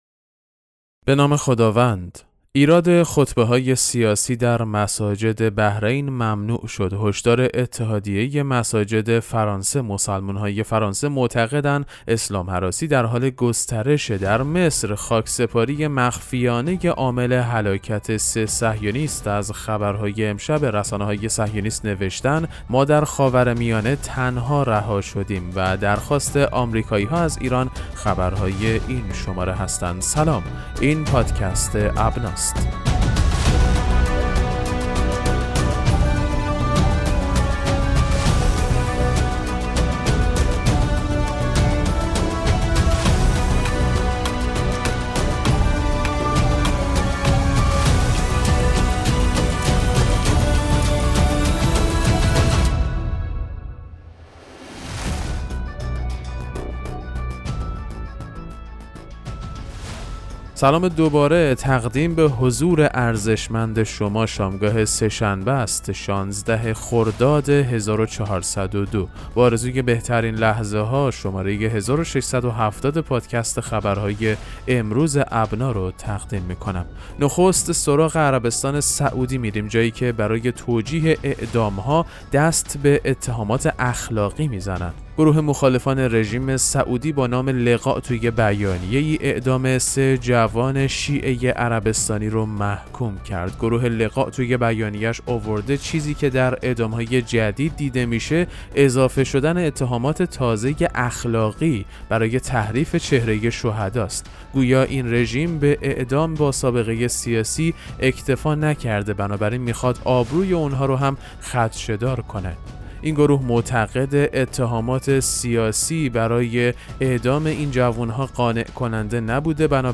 پادکست مهم‌ترین اخبار ابنا فارسی ــ 16 خرداد 1402